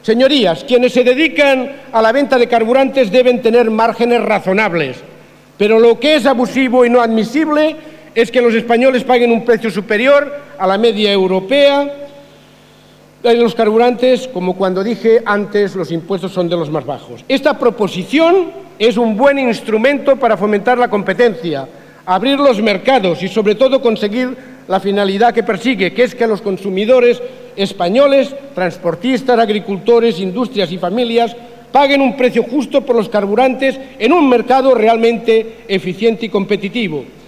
Román Ruiz interviene en el pleno del Congreso en defensa de una iniciativa para reducir el precio de los combustibles